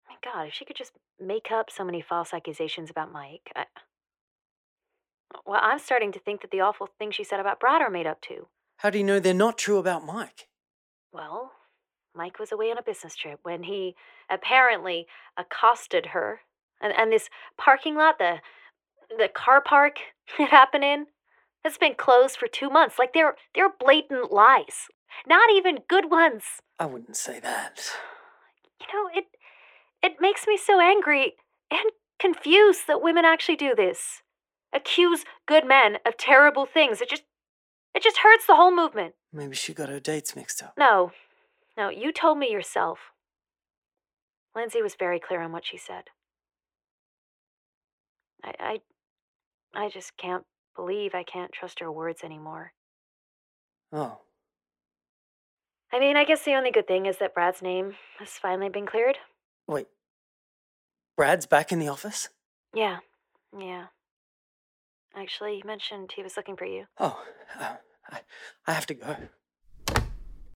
Neumann U87
Soprano